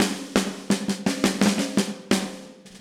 Index of /musicradar/80s-heat-samples/85bpm
AM_MiliSnareC_85-03.wav